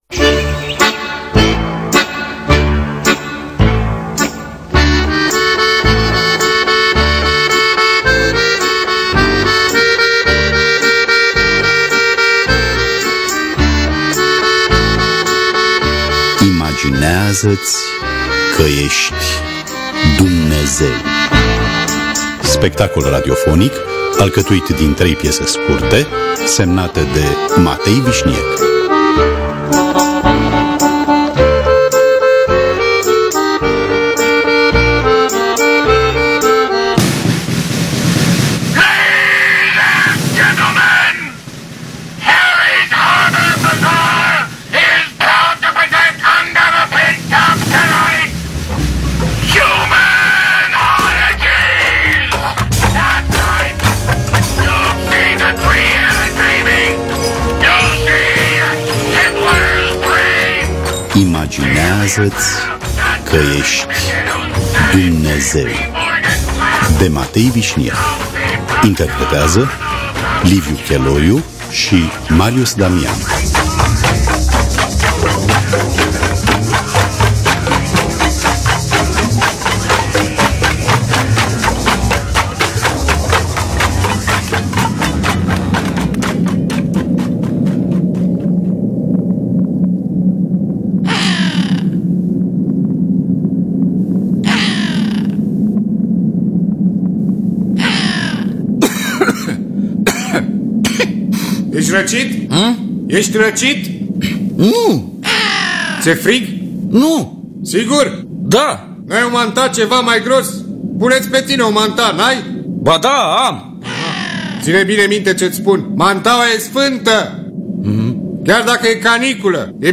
Matei Visniec – Imagineaza-ti Ca Esti Dumnezeu (2009) – Teatru Radiofonic Online